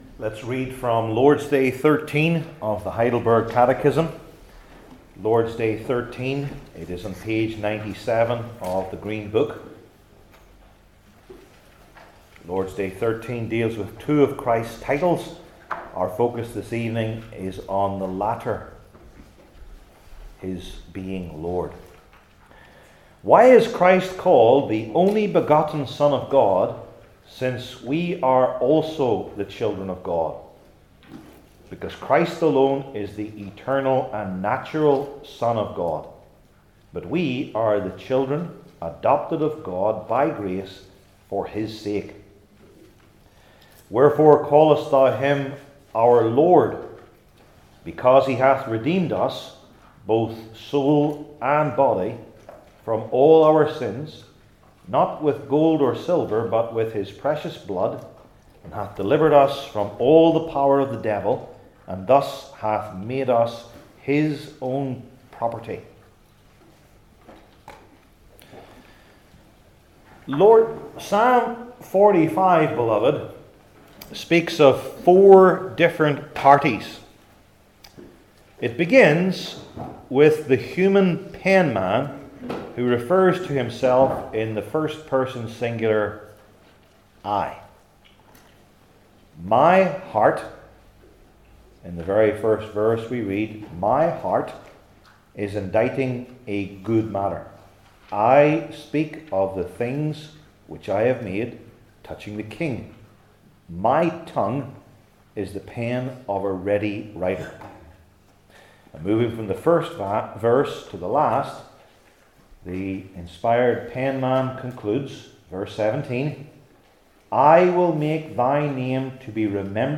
Heidelberg Catechism Sermons I. Who He Is II.